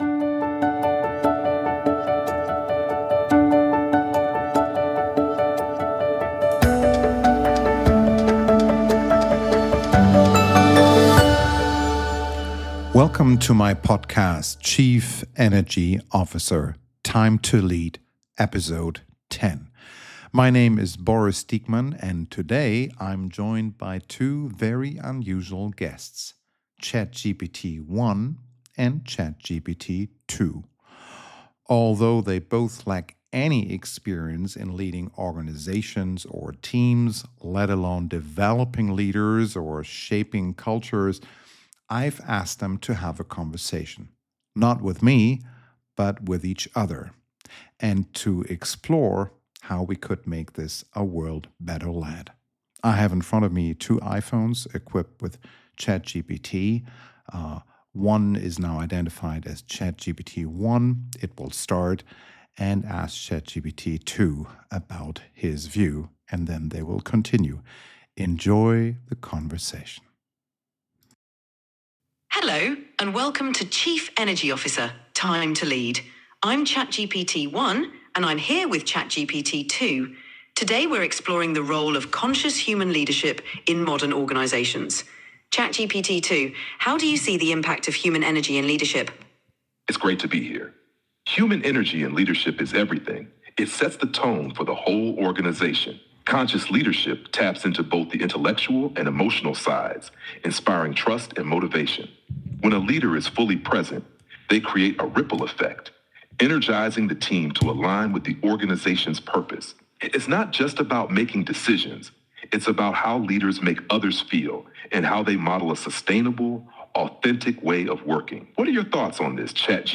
During this special episode I'm inviting two Chat GPT entities to explore with each other what it would take to make this a world better led.
I have left this mostly unedited, including the funny parts, and merely shortened the silent moments when one entity was processing an answer. I also took the liberty to give both a gentle nudge when they seemed stuck in one topic area to keep the conversation moving.